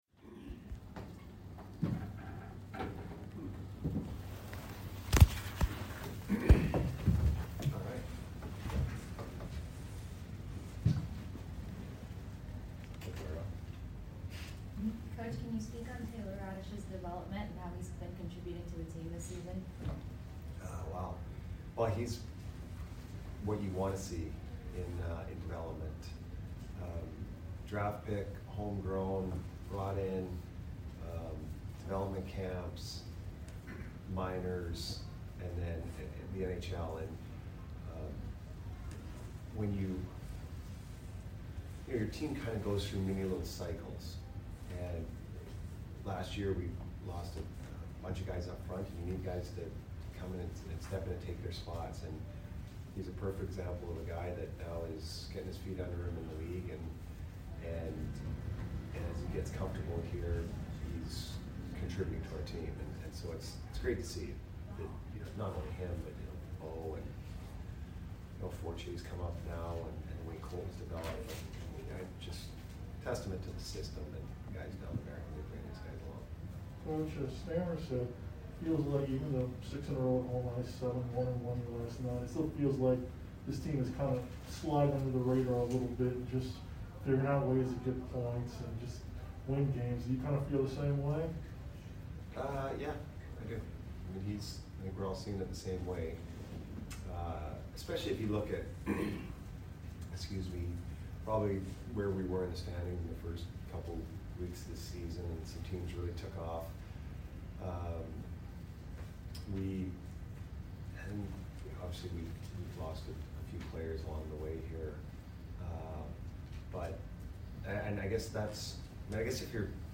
Head Coach Jon Cooper Post Game Vs OTT 12 - 16 - 21